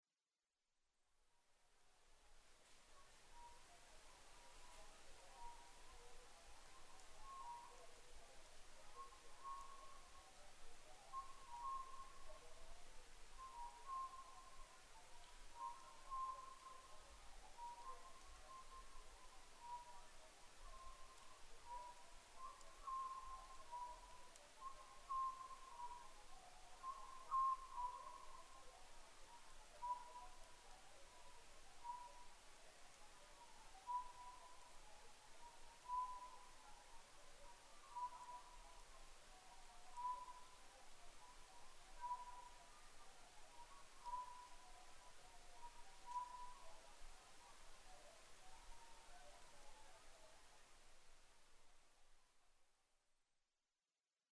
コノハズク　Otus scopsフクロウ科
日光市土呂部　alt=1210m
Mic: Panasonic WM-61A  Binaural Souce with Dummy Head
二羽のコノハズクが鳴き合っています。